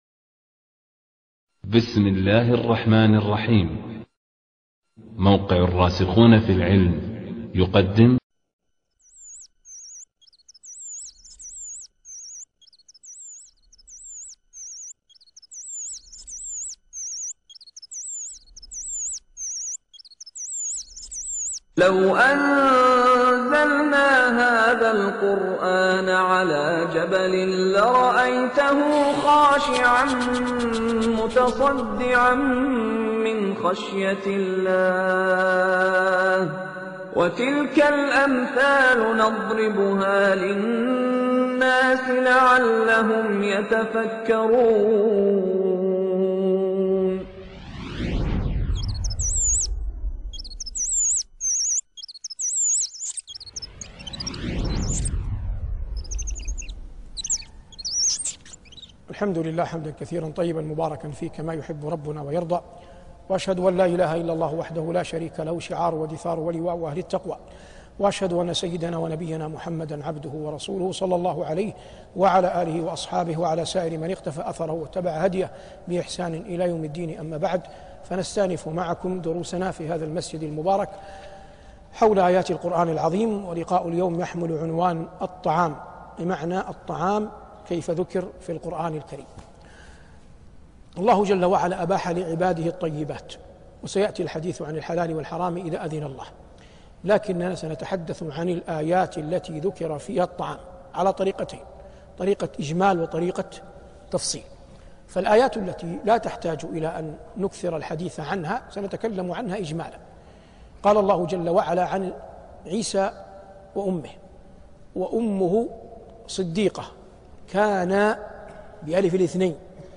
شبكة المعرفة الإسلامية | الدروس | الطعام |صالح بن عواد المغامسي